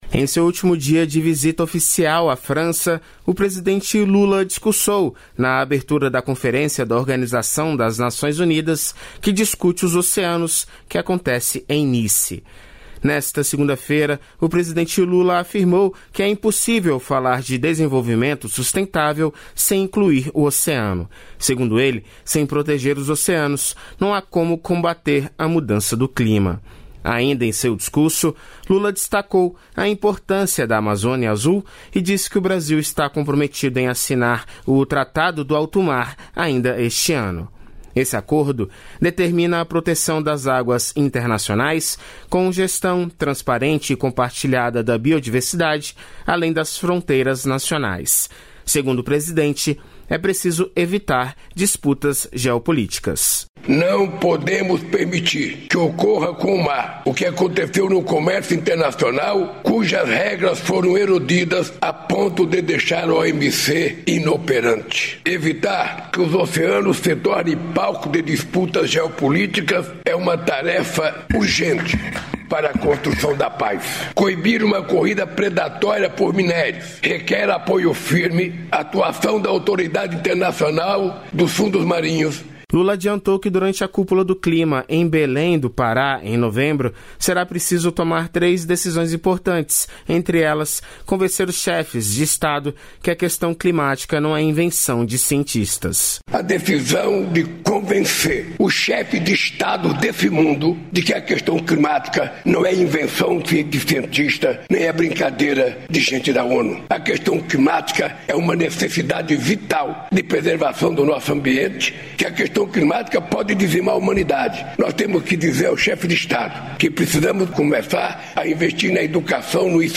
Lula discursa em conferência da ONU e defende proteção dos oceanos